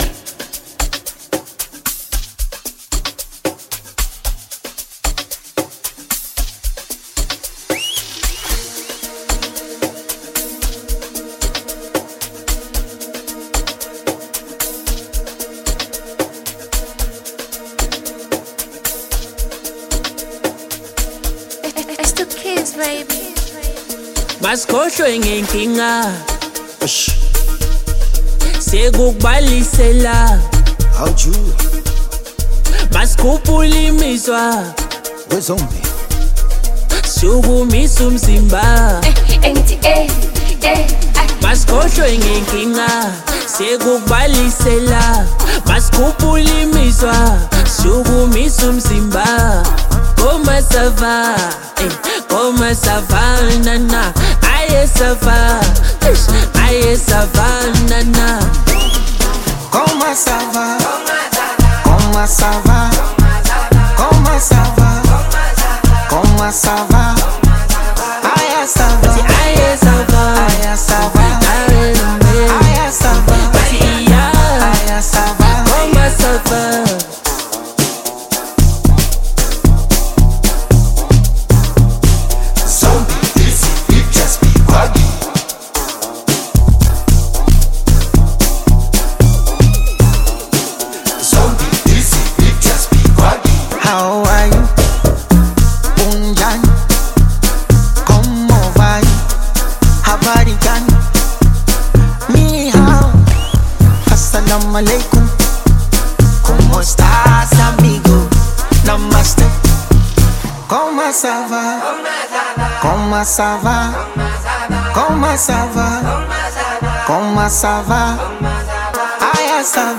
Bongo Flava song